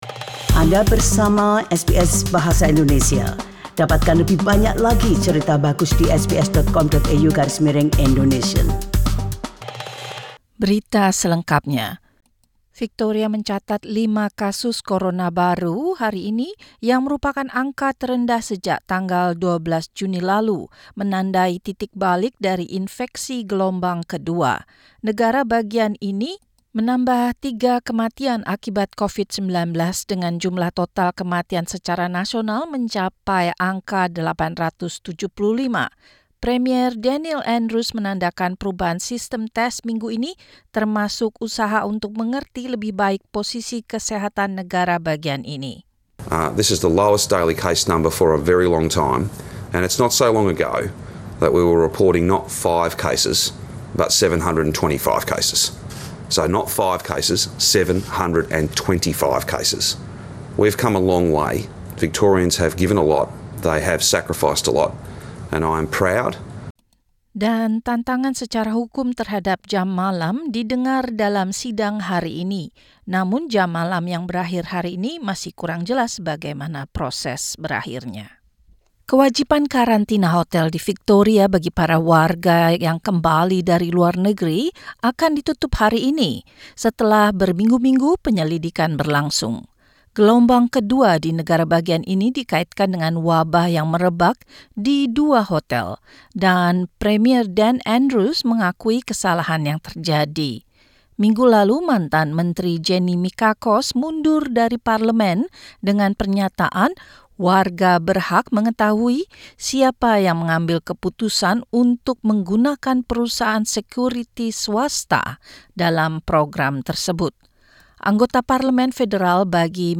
SBS Radio News in Indonesian - 28 September 2020